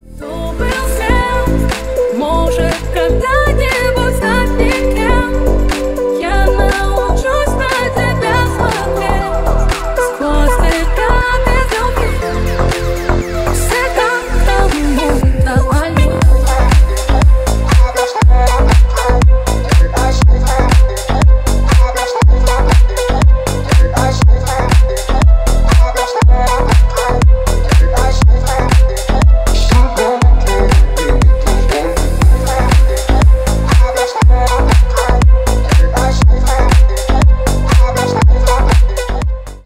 Ремикс
клубные # грустные